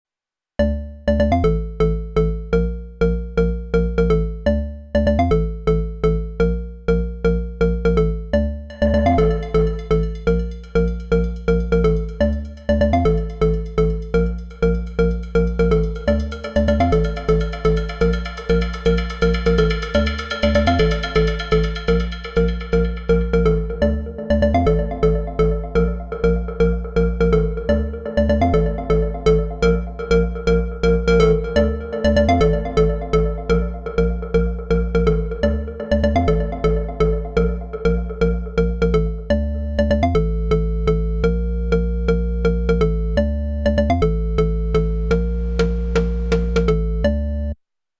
Organ Bass